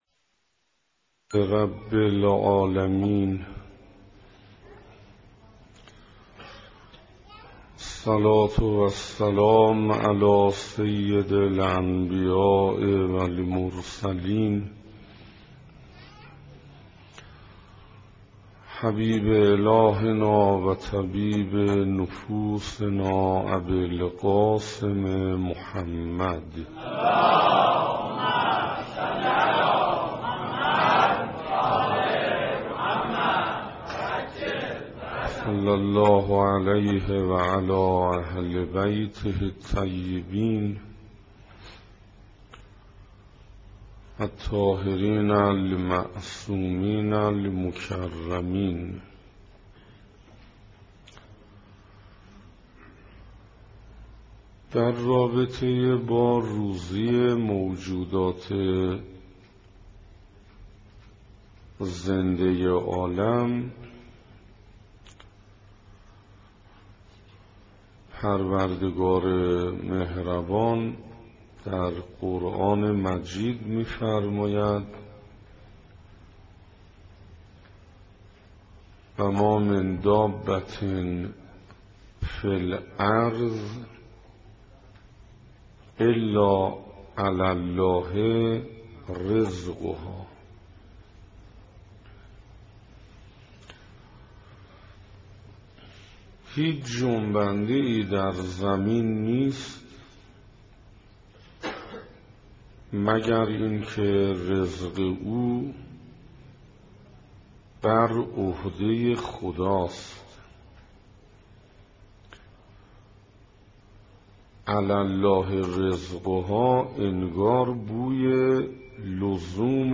سخنراني هفتهم
صفحه اصلی فهرست سخنرانی ها نگاهي به آيات قرآن (2) سخنراني هفتهم (تهران بیت الزهرا (س)) رمضان1427 ه.ق - مهر1385 ه.ش دانلود متاسفم..